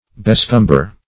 Search Result for " bescumber" : The Collaborative International Dictionary of English v.0.48: Bescumber \Be*scum"ber\, Bescummer \Be*scum"mer\, v. t. [Pref. be- + scumber, scummer.]